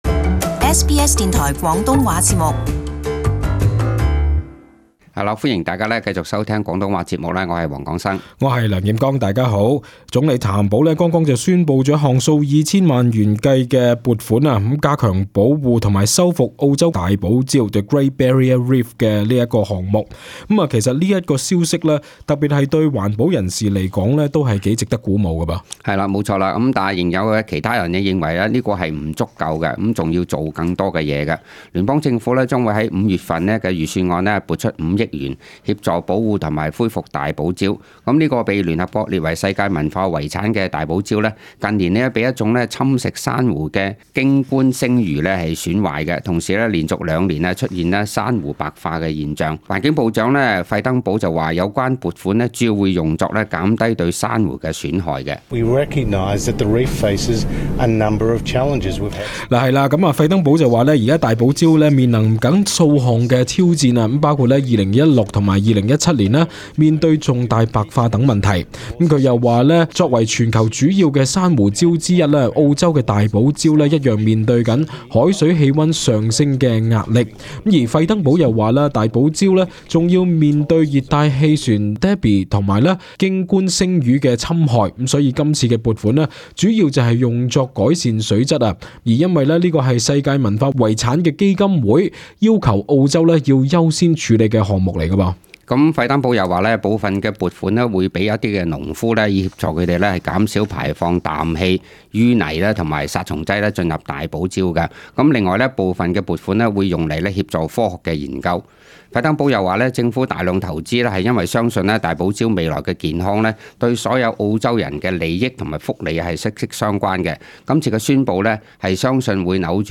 【時事報道】聯邦政府撥出5億元保護大堡礁